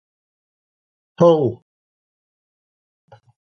Pronunciado como (IPA)
/hʌl/